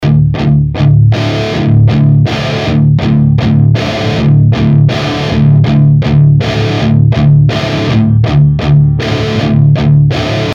(un boitier de reamp dans un cas et dans l'autre il est remplacé par la sortie casque)
Le rv2 semble plus propre et ready to mix, alors que l'autre est dégueulasse dans les basses, sature...